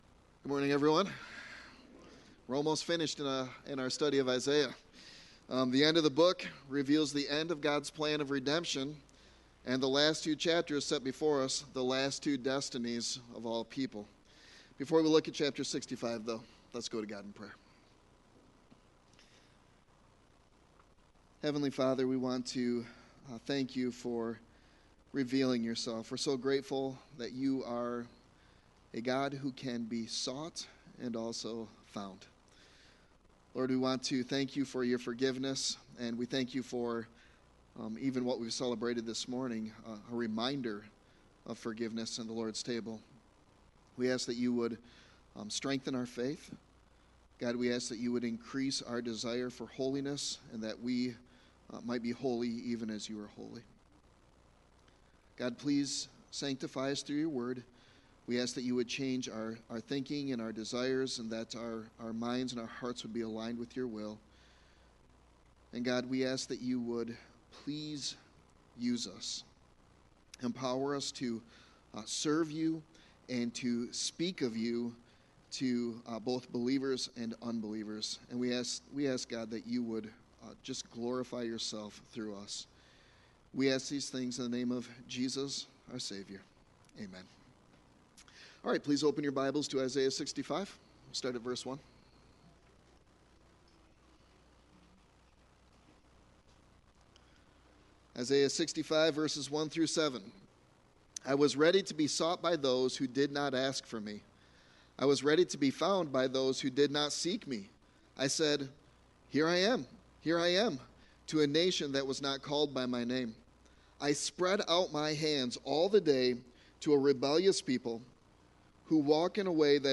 Sermon Text: Isaiah 65:1-25